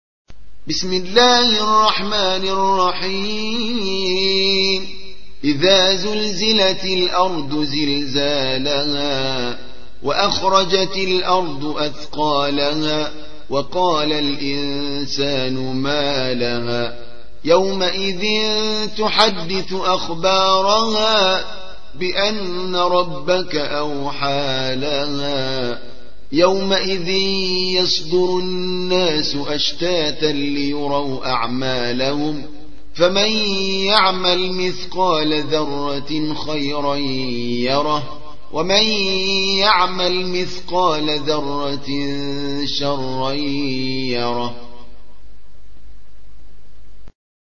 99. سورة الزلزلة / القارئ